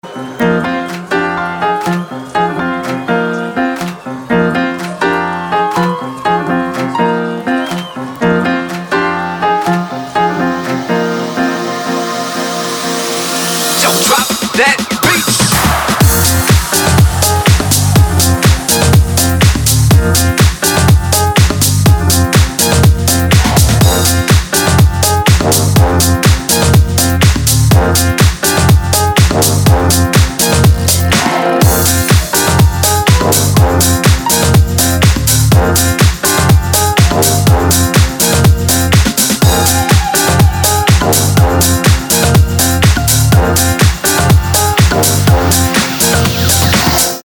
• Качество: 320, Stereo
без слов
инструментальные
house
рояль
Красивый мотив на рояле под хаус-музыку